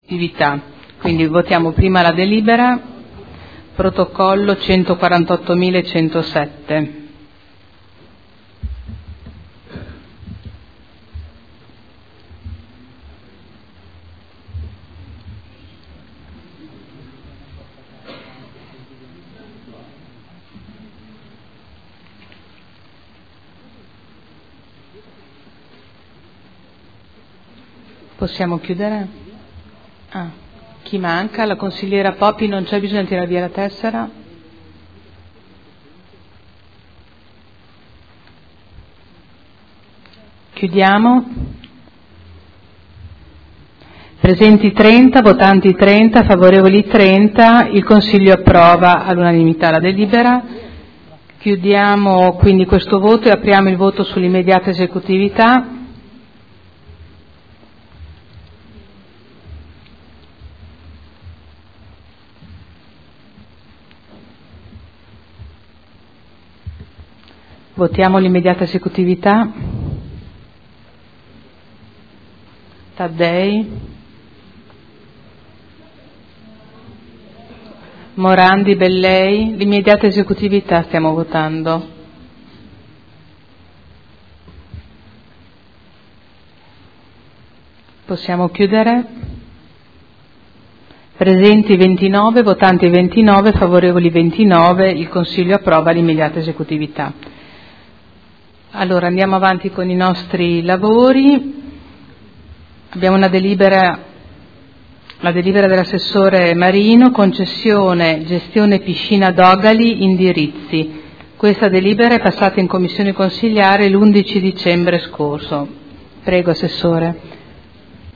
Seduta del 16 gennaio. Proposta di deliberazione: Convenzione per l’utilizzo del Difensore Civico Territoriale – Approvazione.